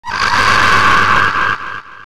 Scary Scream!! Sound Button - Free Download & Play
Sound Effects Soundboard4,560 views